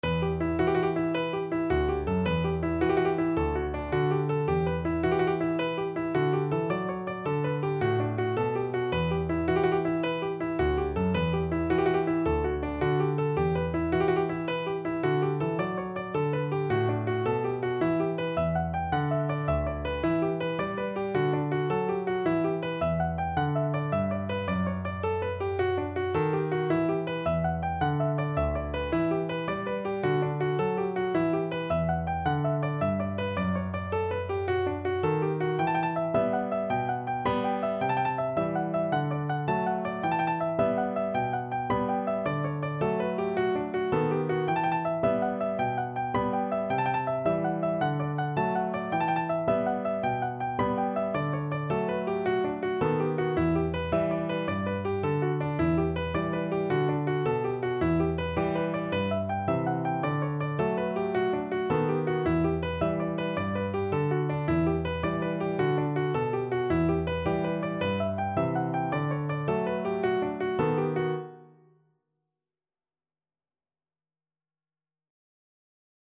No parts available for this pieces as it is for solo piano.
6/8 (View more 6/8 Music)
Piano  (View more Intermediate Piano Music)